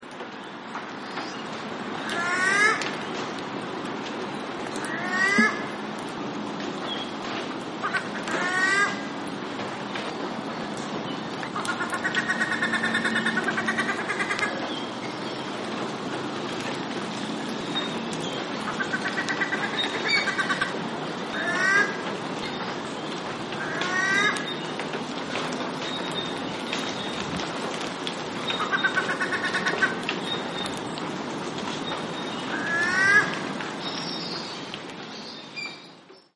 Australian Wood Duck - Chenonetta jubate
Voice: rising, nasal 'gnow', staccato chutter.
Call 1: A duck gives the 'gnow' call several times, then chutters before giving more 'gnow' calls. A Crimson Rosella calls a few times in the background and raindrops punctuate the recording.
Aus_Wood_Duck.mp3